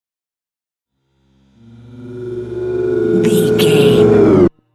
Technologic riser human robot
Sound Effects
Atonal
bouncy
futuristic
intense
tension
riser
sci fi